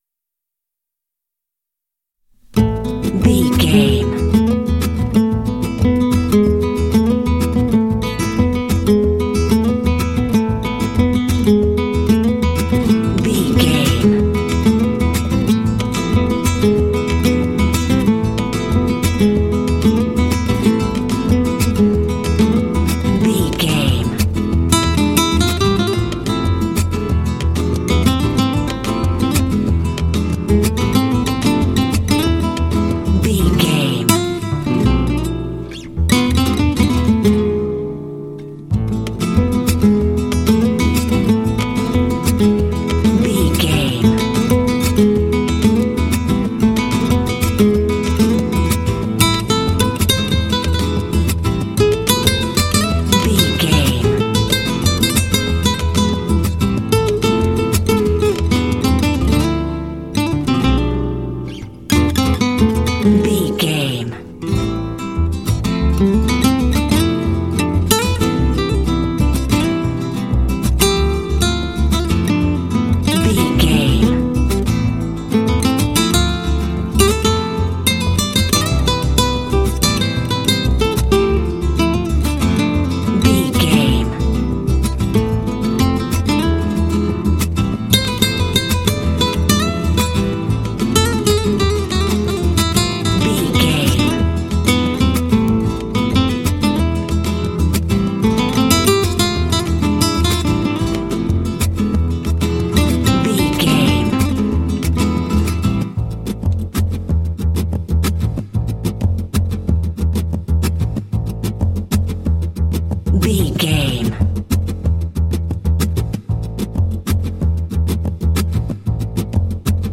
This smooth track is great for racing games
Uplifting
Aeolian/Minor
smooth
acoustic guitar
bass guitar
latin
Lounge
downtempo